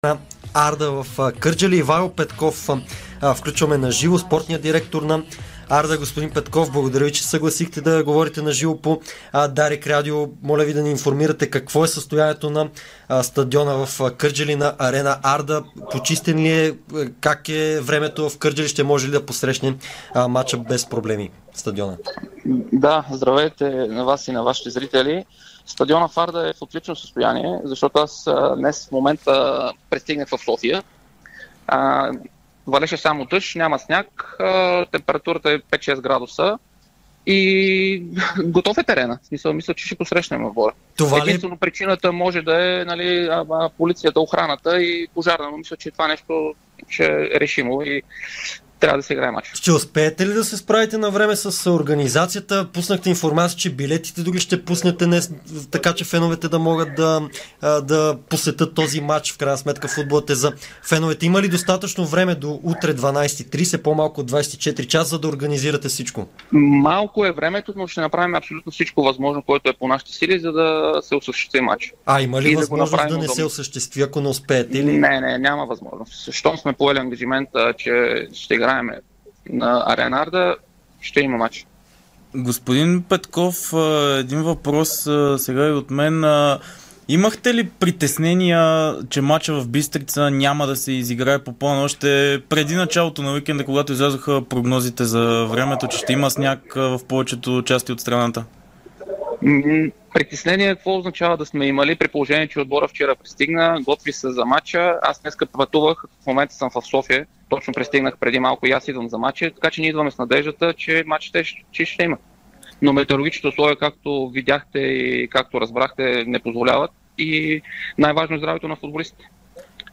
Спортен директор на Арда Ивайло Петков говори ексклузивно в ефира на Дарик радио в разширеното издание на „Спортното шоу на Дарик“ минути, след като стана ясно, че двубоят между тима от Кърджали и ЦСКА 1948 на стадион „Витоша“ в Бистрица от 18-ия кръг в efbet Лига е отложен.